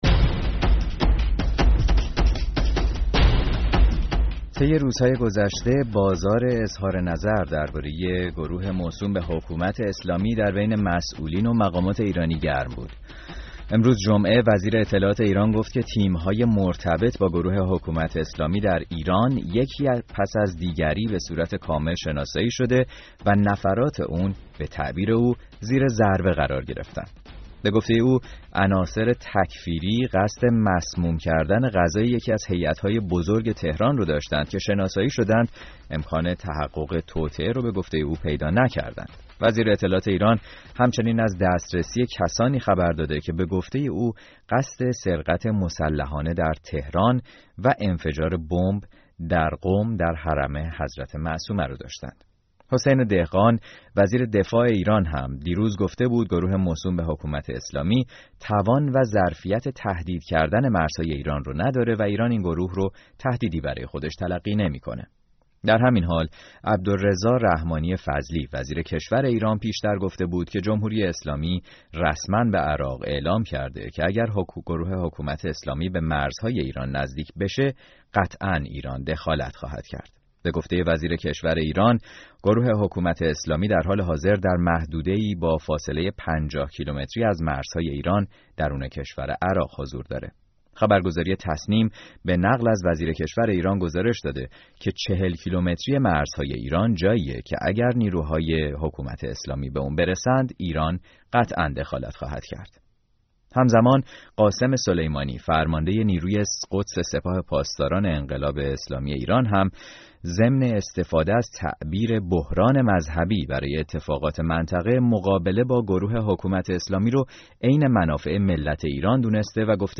کارشناس نظامی از بریتانیا و مخاطبان رادیو فردا بود.